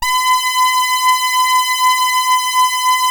83-SAWRESWET.wav